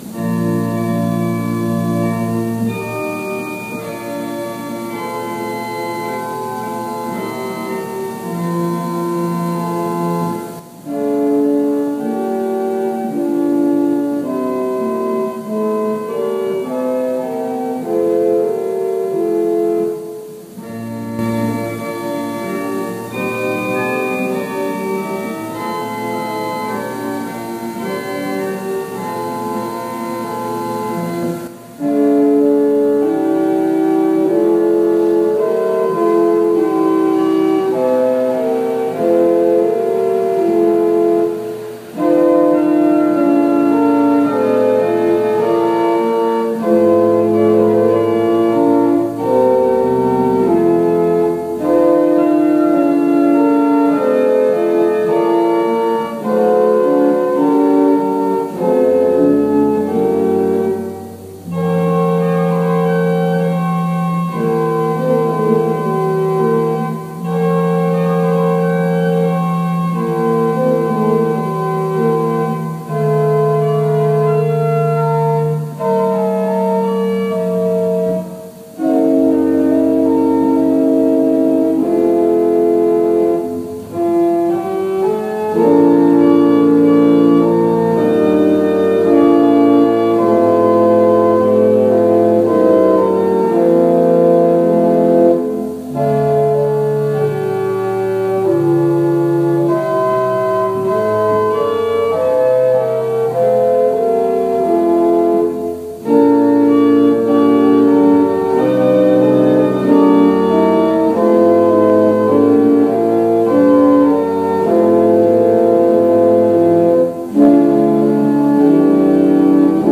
Worship Service May 2, 2021 | First Baptist Church, Malden, Massachusetts
Prelude
Call to Worship & Invocation & Lord’s Prayer Hymn
Psalm 126 Sermon